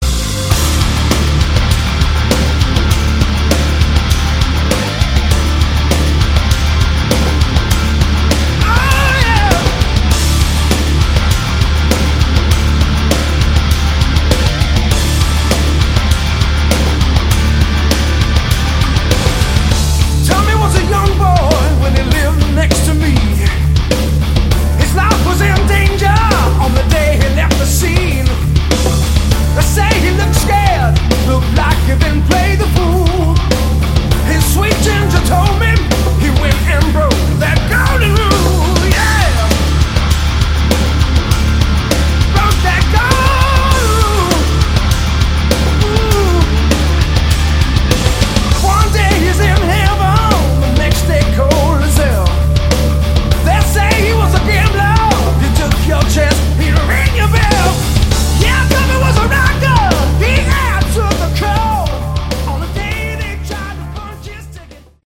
Category: Melodic Metal